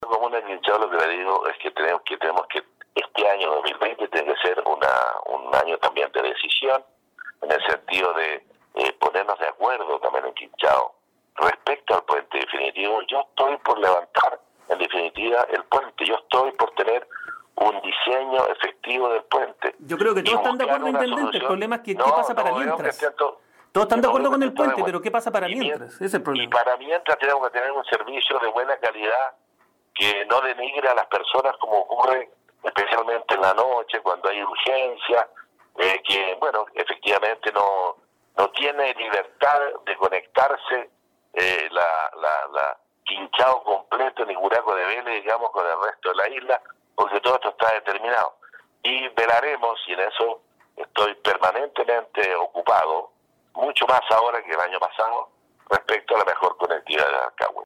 Así se desprende por otra parte, de las palabras del intendente Harry Jurgensen, quien planteó estas demandas en el consejo de gabinete ampliado realizado recientemente por el presidente Sebastián Piñera, como lo expresó en entrevista con radio Estrella del Mar de Achao.